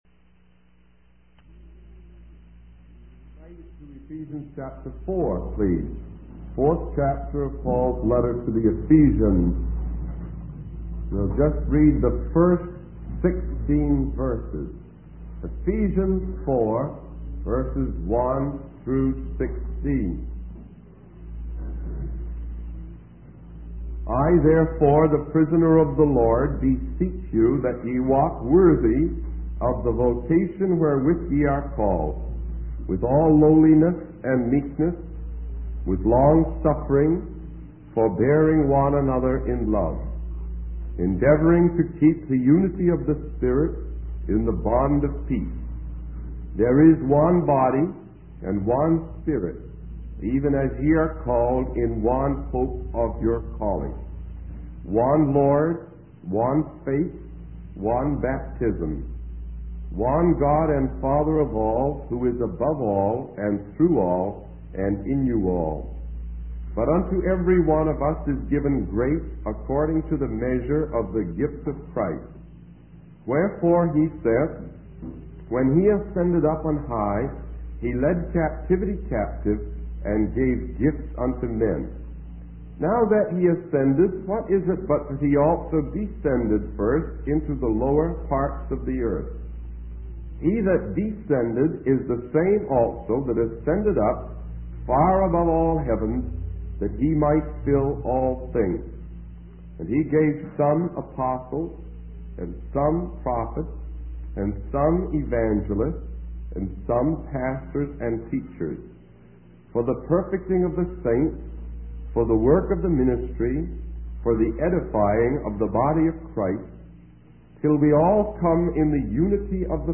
In this sermon, the speaker outlines the structure of the book of Romans, dividing it into two parts.